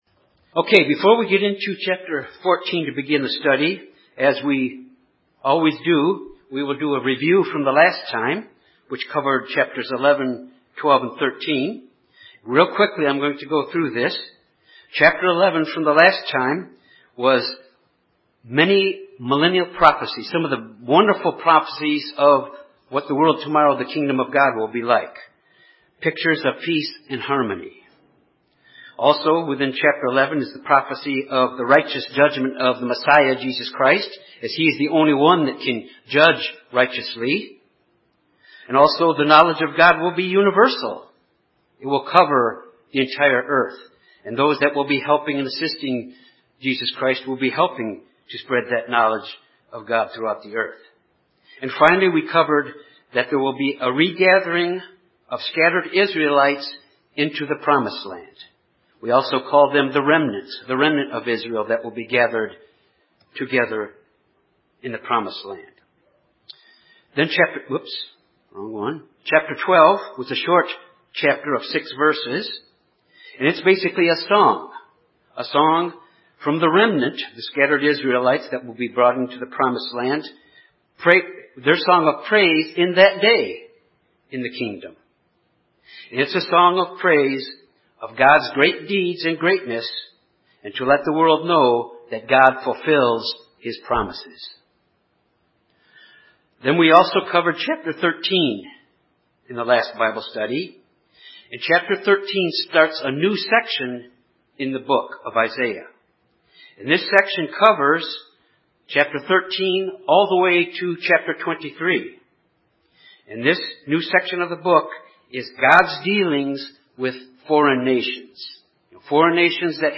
This Bible study looks at the end-time prophecies of Isaiah concerning Babylon, Assyria, Philistia, and Moab.